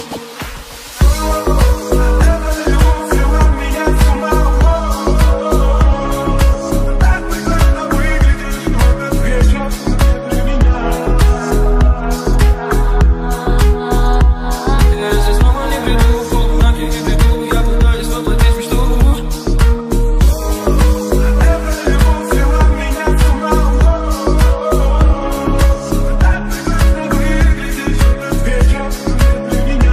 Жанр: Поп
# Pop